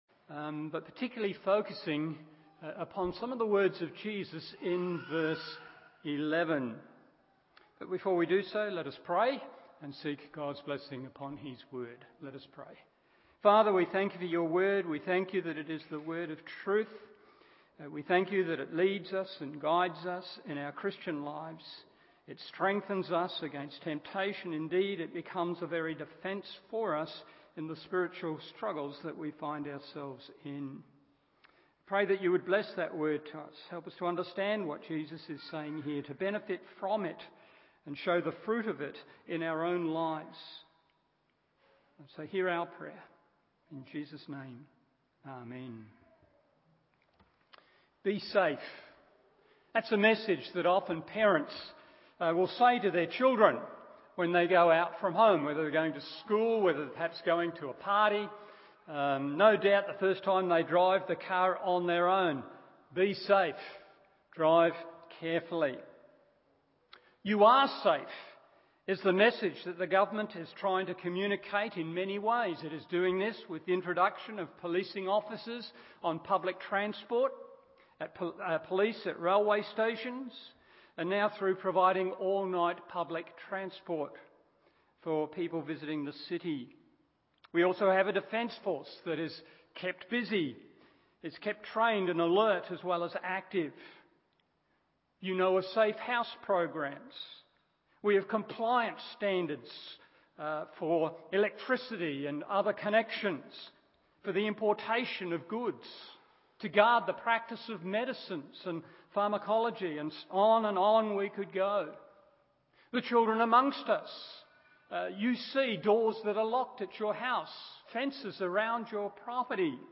Morning Service John 17:11b 1.